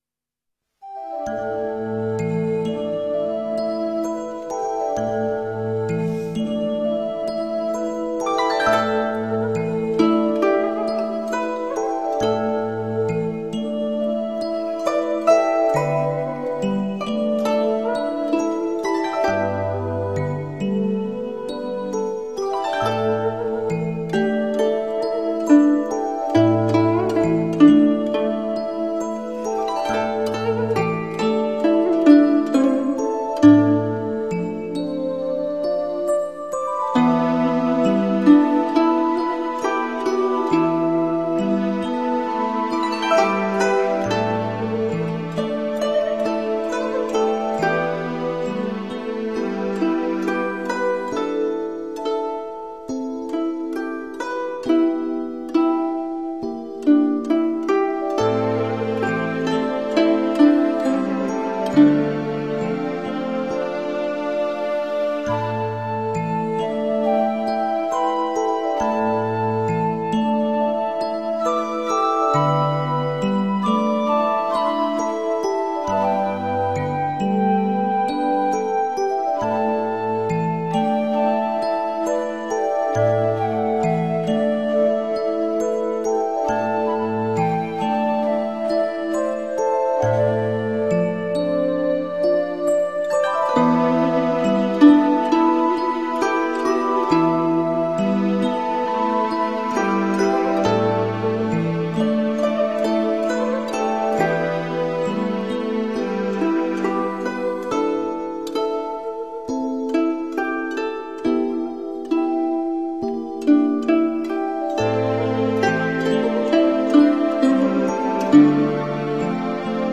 佛音 诵经 佛教音乐 返回列表 上一篇： 水在瓶(菩薩偈